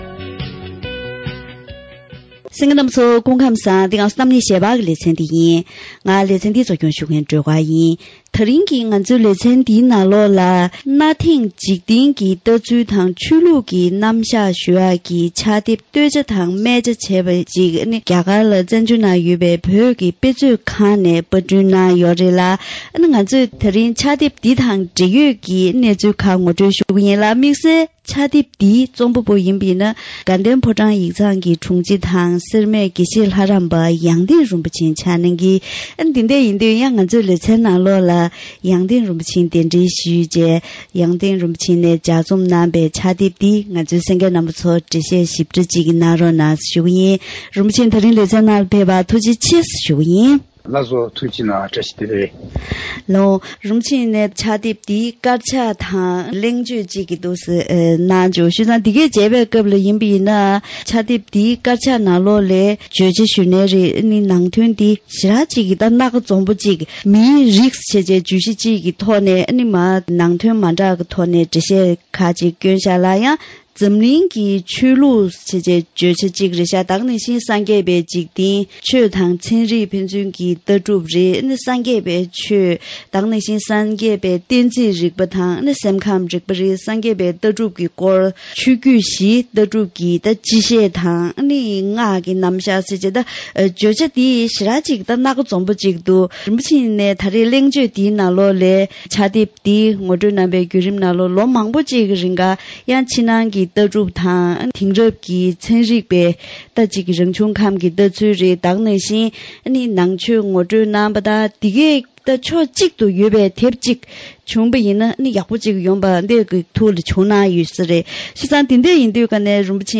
ཐེངས་འདིའི་གཏམ་གླེང་ཞལ་པར་ལེ་ཚན་ནང་།